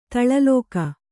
♪ taḷa lōka